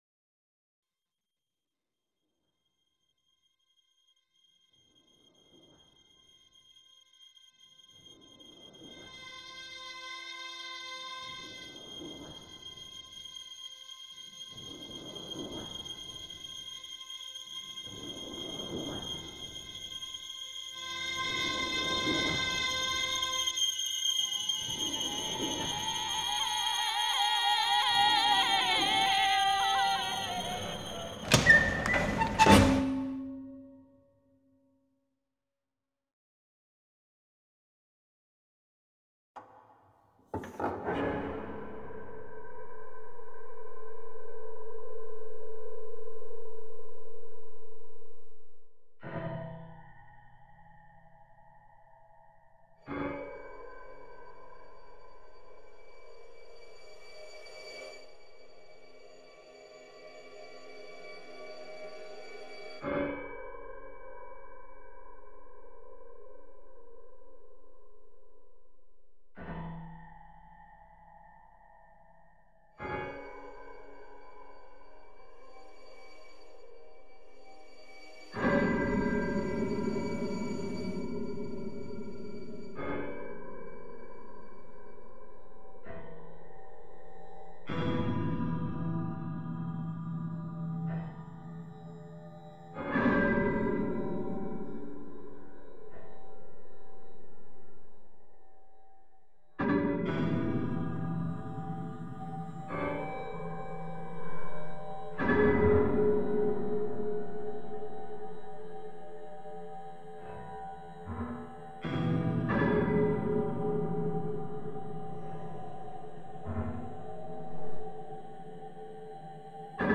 8-channel electroacoustic composition